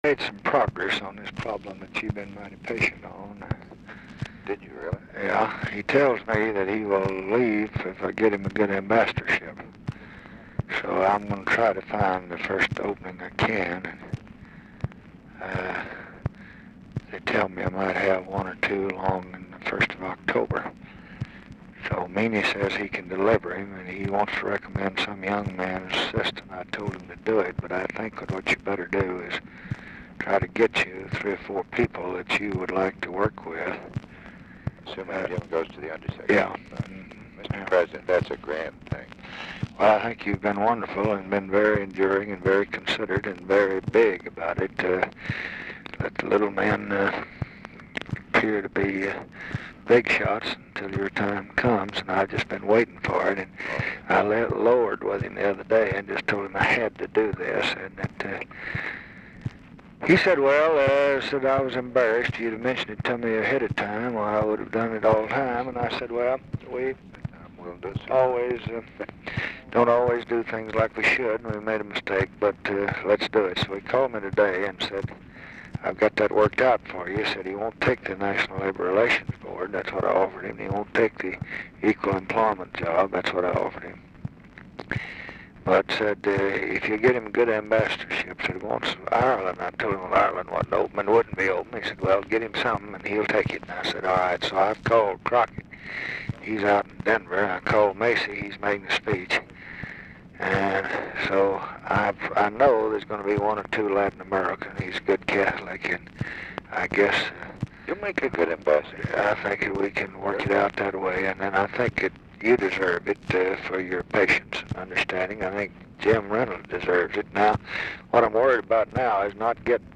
Telephone conversation # 10619, sound recording, LBJ and WILLARD WIRTZ, 8/17/1966, 2:42PM | Discover LBJ
Format Dictation belt
Location Of Speaker 1 Mansion, White House, Washington, DC
Specific Item Type Telephone conversation